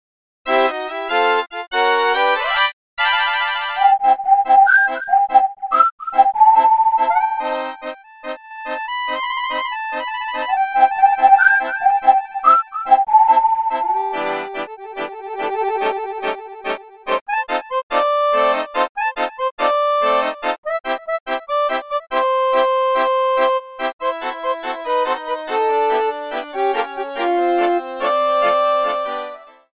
Trimmed, added fadeout
Fair use music sample